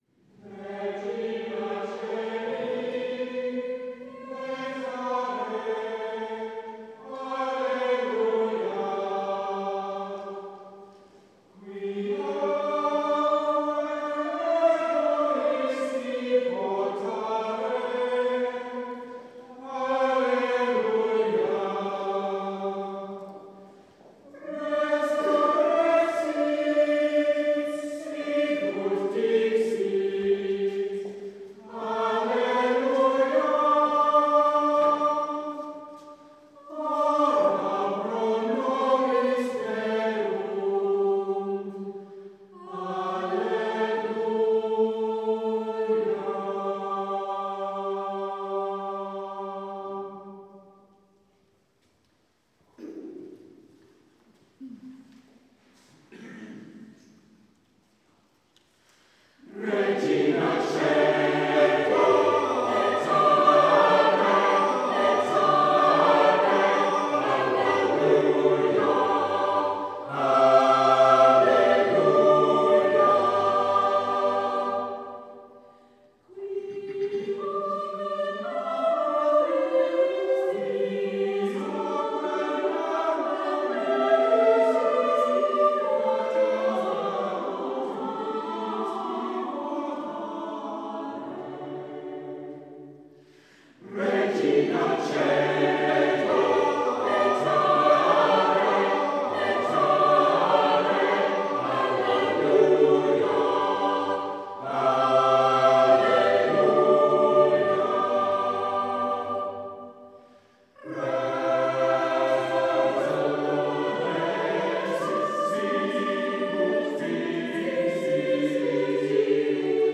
The Choir of Boxgrove Priory
with The Boxgrove Consort of Viols
Recorded live in Boxgrove Priory on the evening of 25th June 2013
Regina caeli - plainsong - followed by Regina caeli - Aichinger